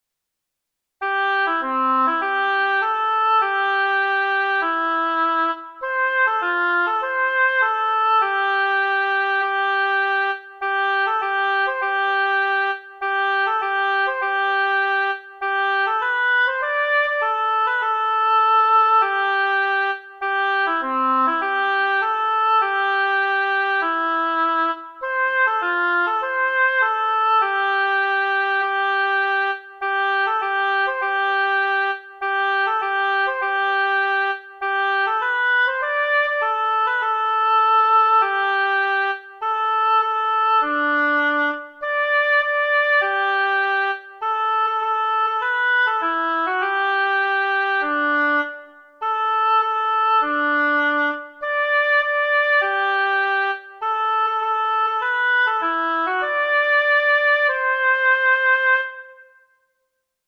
阿佐谷幼稚園創立100周年記念写真展の開催にあわせて、昔歌われていた「阿佐ヶ谷幼稚園園歌」と「阿佐谷幼稚園のうた」の音声ファイル（メロディのみ、今後歌詞を掲載）を掲載しました。